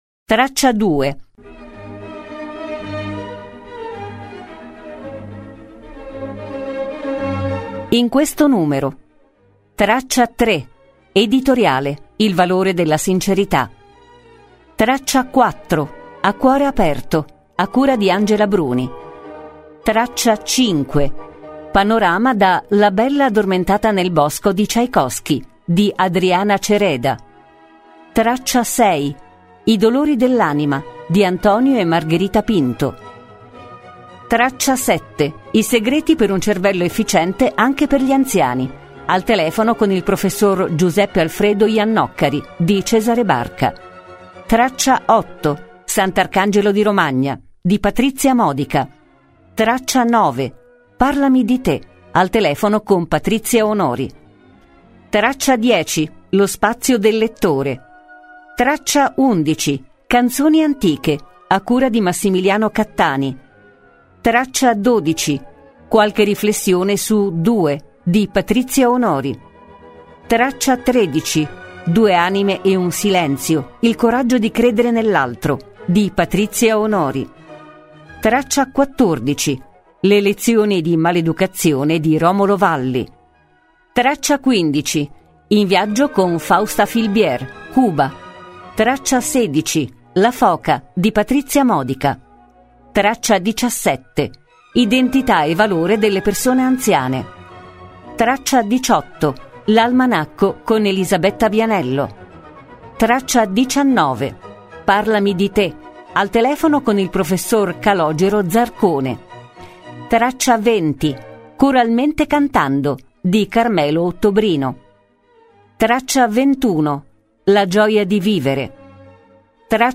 Questo numero è particolarmente ricco per una spinta davvero essenziale e sostanziale dei nostri collaboratori. Questo numero può essere assaporato in una lettura ricca di musica